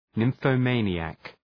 Προφορά
{,nımfə’meınıæk}
nymphomaniac.mp3